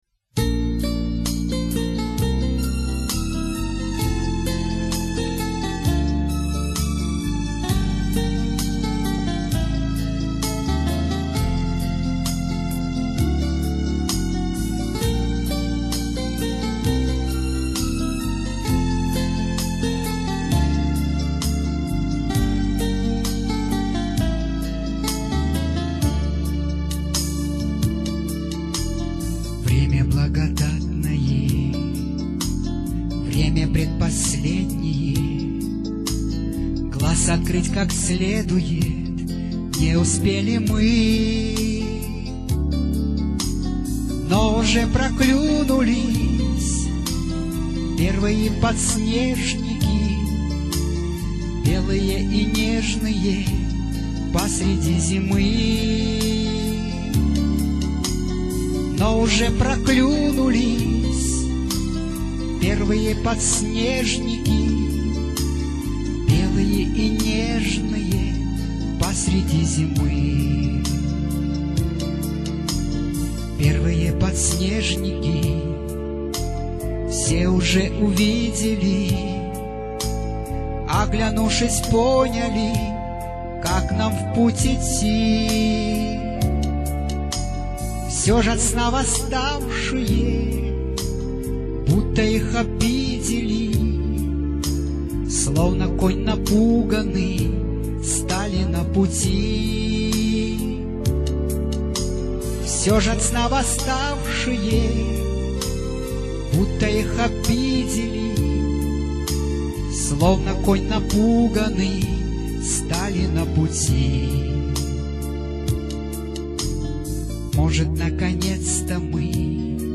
Православная музыка